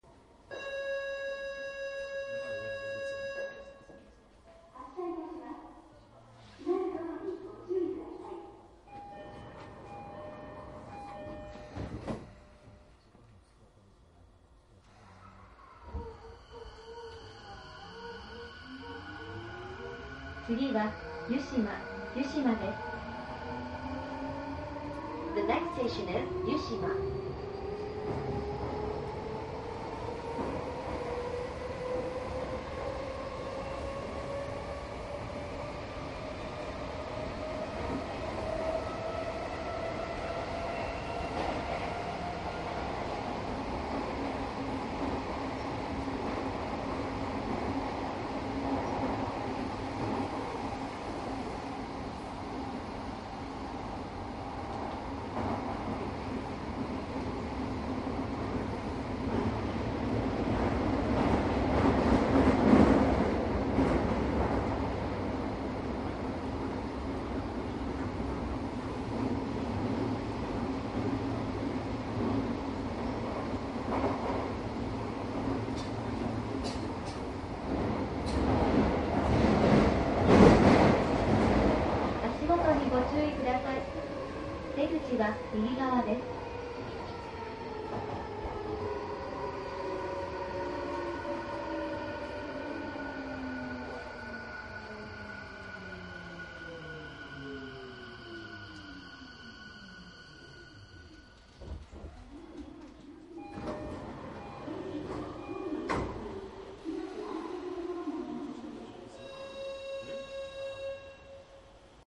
小田急4000形『東京メトロ千代田線』走行音 ＣＤ♪
録音機材とマイクは変わってませんので音質のクオリティーに違いはありません。
■【各駅停車】綾瀬→代々木上原 4404
マスター音源はデジタル44.1kHz16ビット（マイクＥＣＭ959）で、これを編集ソフトでＣＤに焼いたものです。